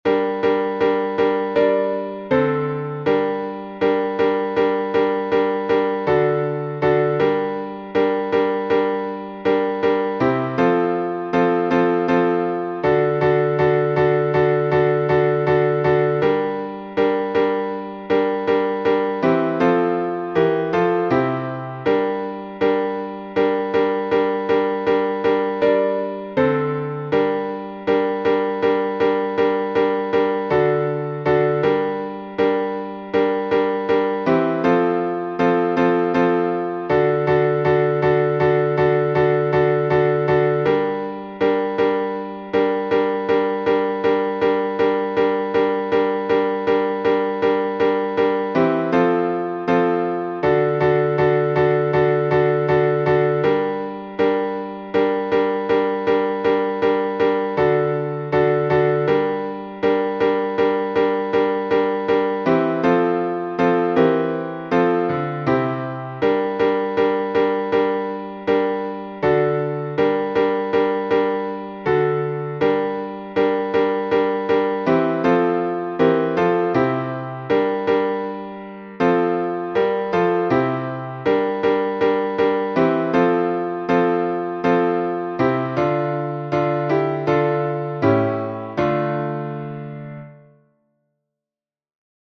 Обиходный напев
глас 2